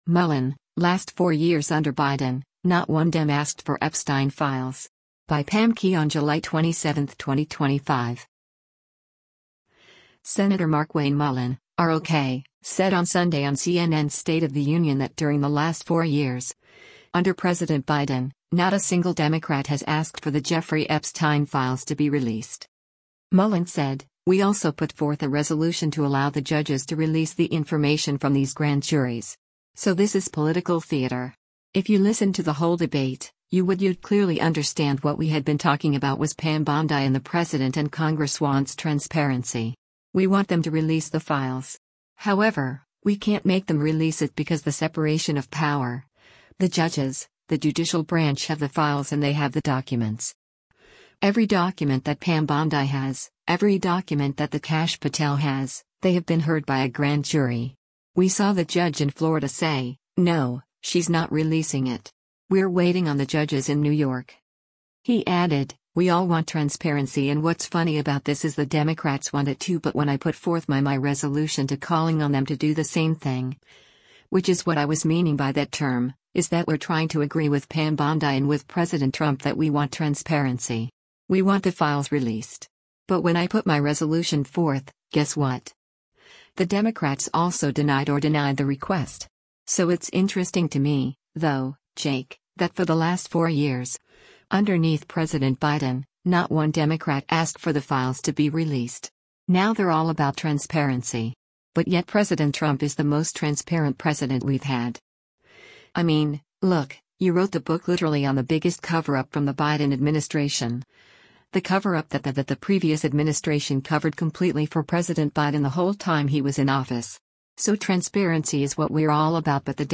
Senator Markwayne Mullin (R-OK) said on Sunday on CNN’s “State of the Union” that during the last four years, under President Biden, not a single Democrat has asked for the Jeffrey Epstein files to be released.